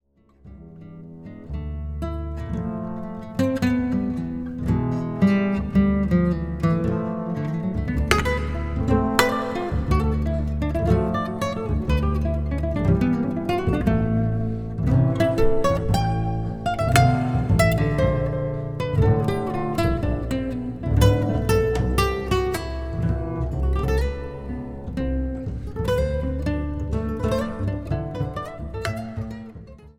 Double Bass